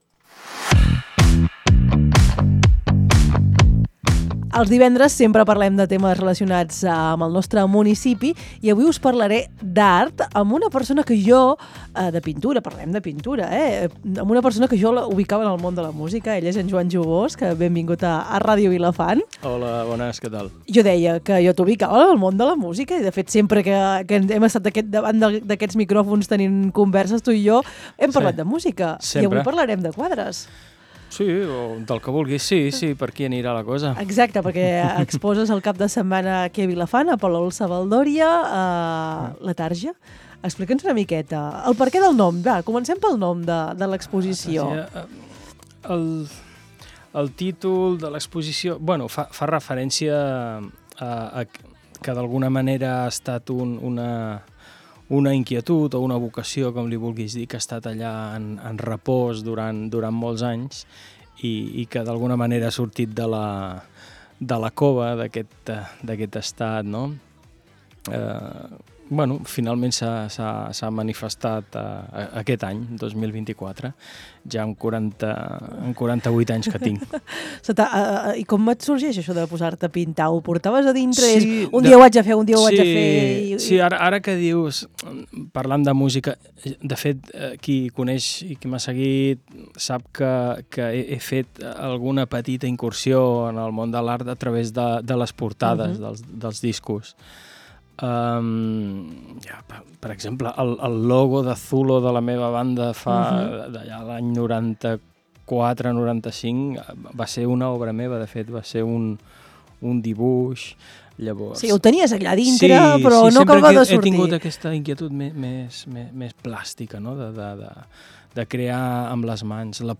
En parlem amb l’artista a les veus del matí.